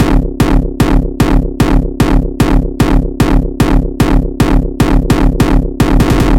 小鼓建造系列3 1
描述：这是与Hardstyle音乐相配的一个简短构建。
标签： 150 bpm Hardstyle Loops Drum Loops 1.08 MB wav Key : Unknown
声道立体声